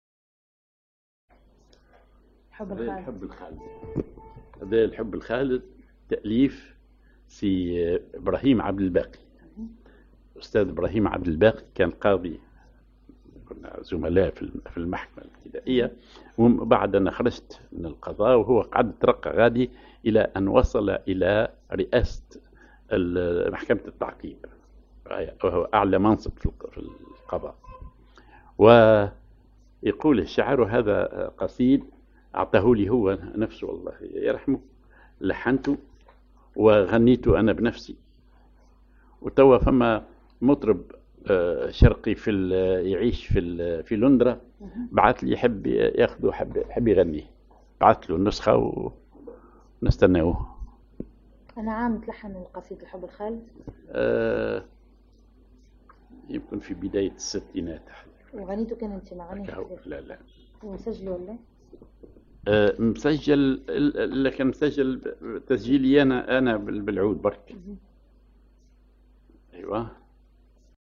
Maqam ar هزام
Rhythm ar وحدة
genre أغنية